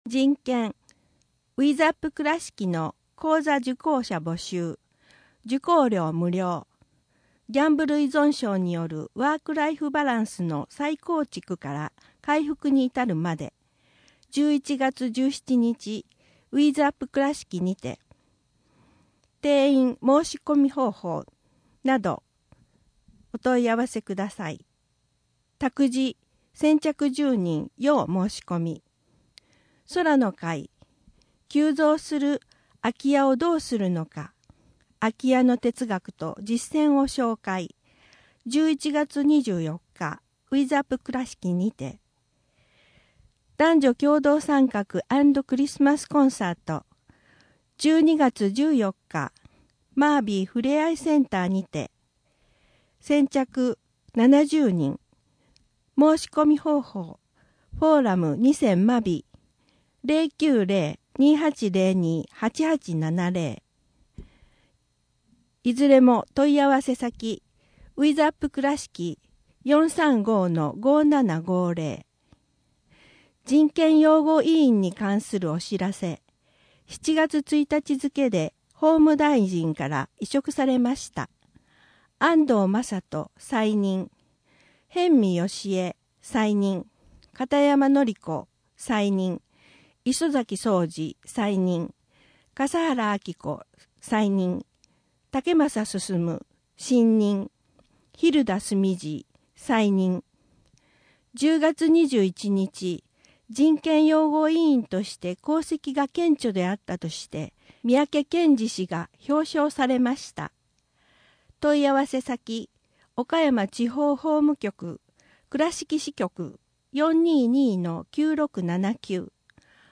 2024年広報くらしき11月号（音訳版）